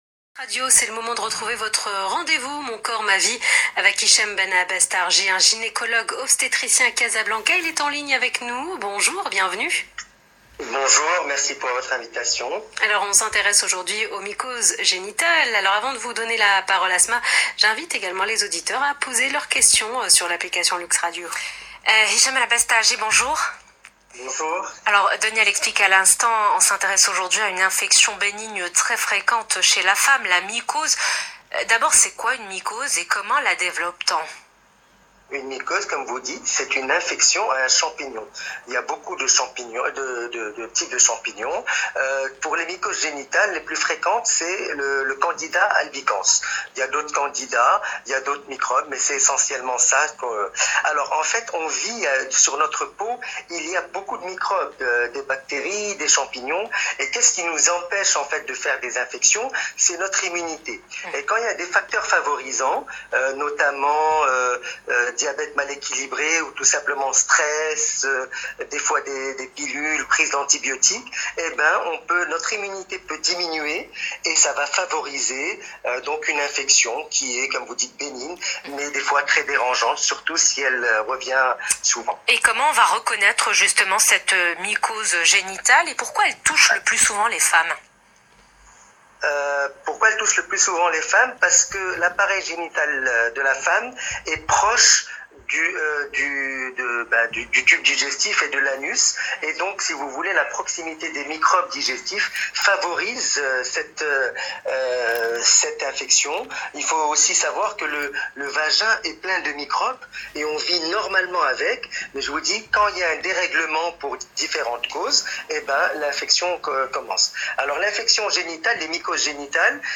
Essai de réponse au cours de cette interview dans l’Heure essentielle sur LUXE RADIO